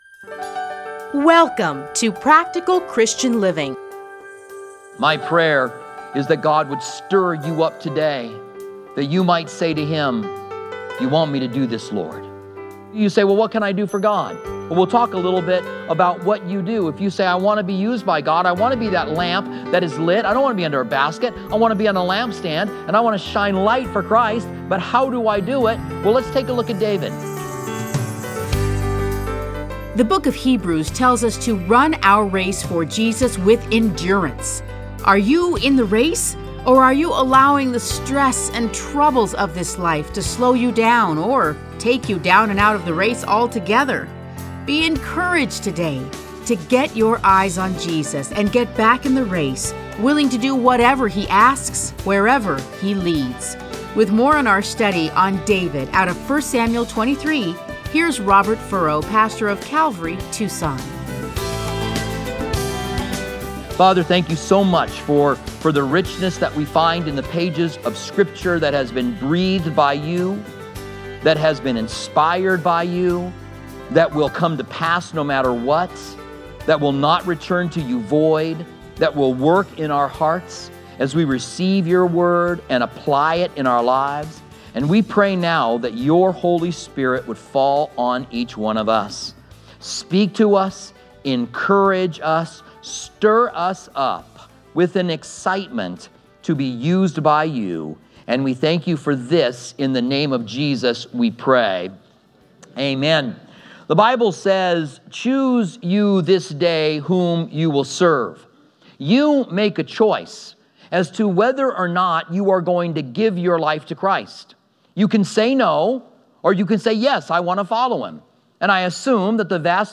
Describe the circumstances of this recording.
teachings are edited into 30-minute radio programs titled Practical Christian Living. Listen to a teaching from 1 Samuel 23:1-29.